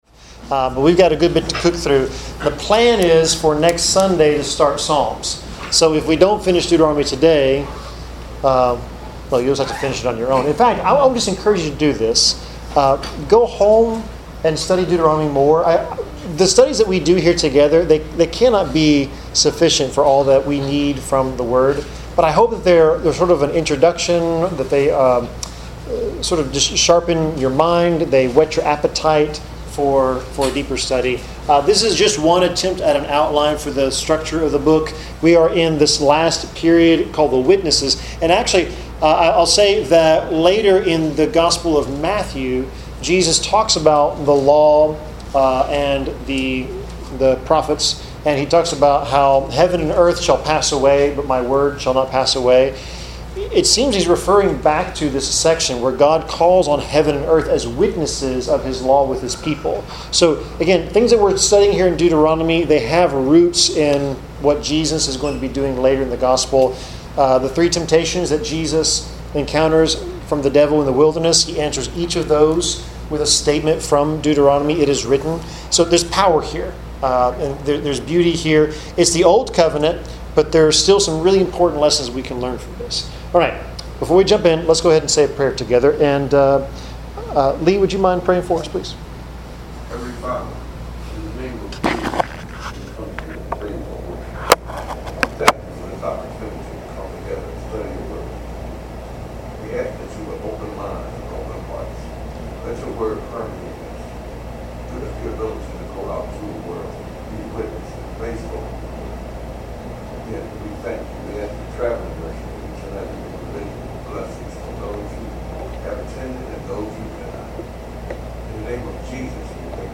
Bible class: Deuteronomy 29-34
Service Type: Bible Class